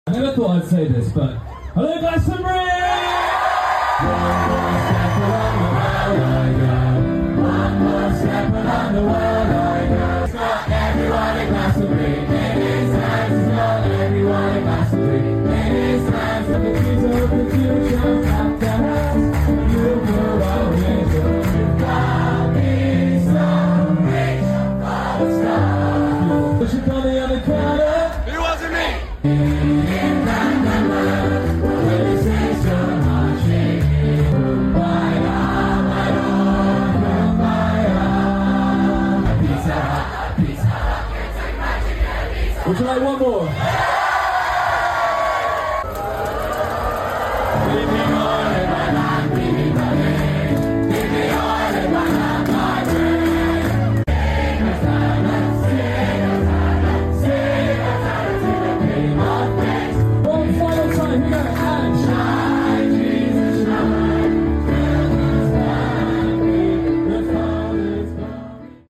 Honestly watching this back just makes me want to bottle up that joyous feeling of an entire field of people singing at the top of their lungs. Here’s a quick highlight reel.